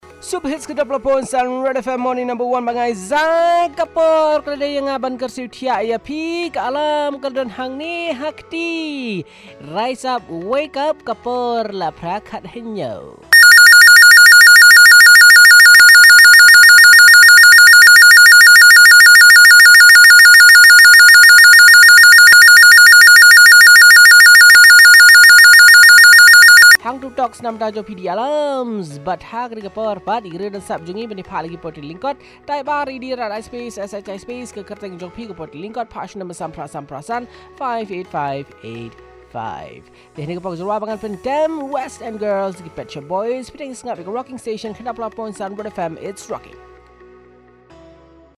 wakes up the callers with his alarm and pre sells Short poem